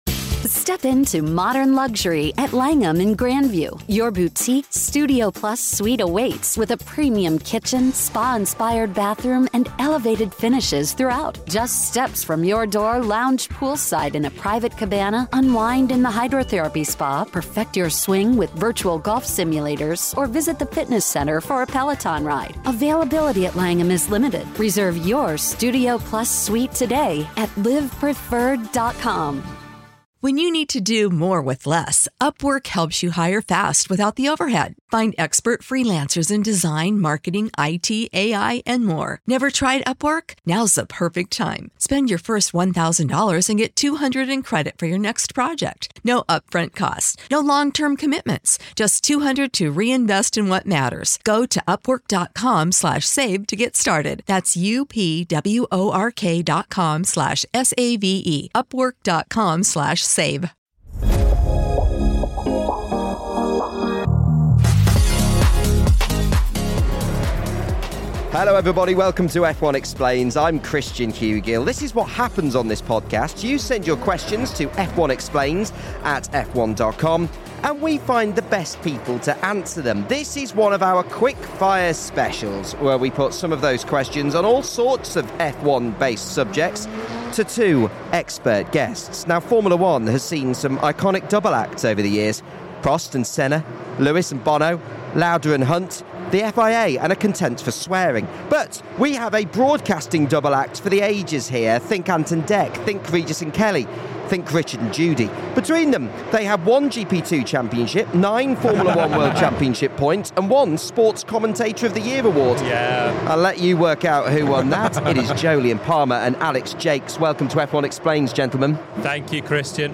In this 'mega' episode, F1TV commentary duo Alex Jacques and Jolyon Palmer explain how they do their jobs - the camera shots and data they look at during a Grand Prix